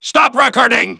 synthetic-wakewords
ovos-tts-plugin-deepponies_Soldier_en.wav